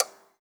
clock_tock_01.wav